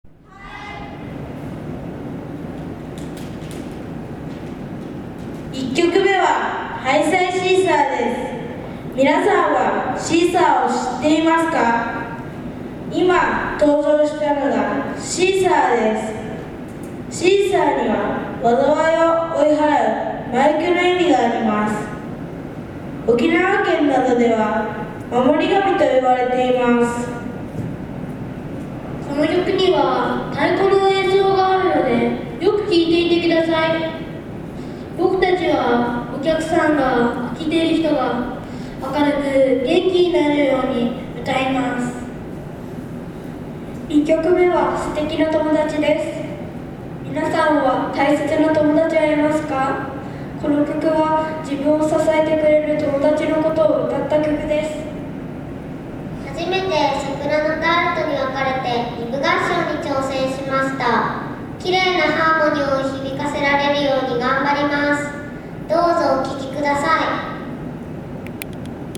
１１月１９日（土）の歌声発表会第２弾！４年生と２年生の発表をお届けします。